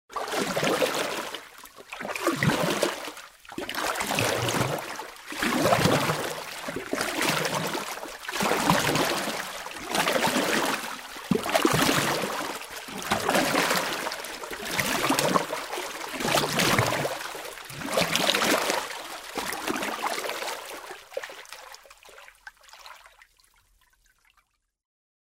Звуки весла
На этой странице собраны звуки весла: плеск воды, ритмичные гребки и другие умиротворяющие аудиоэффекты.